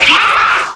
c_alien_hit3.wav